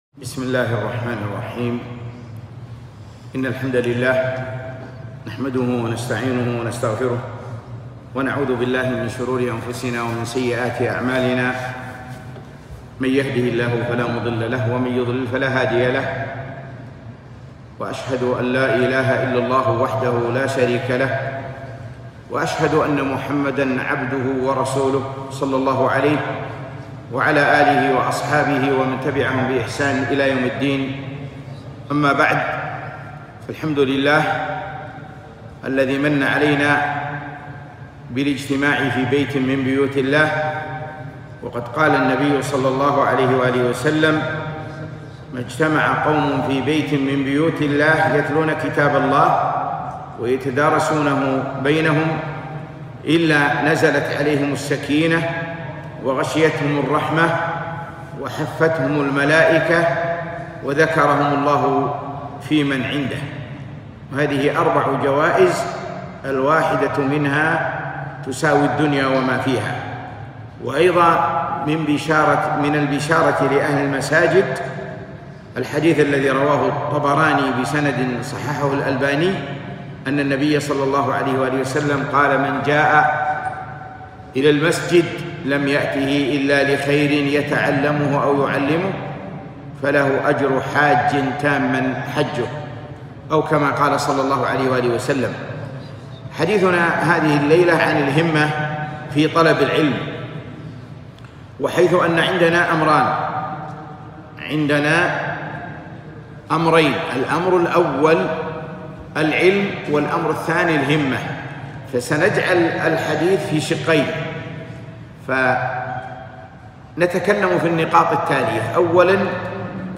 محاضرة - الهمة في طلب العلم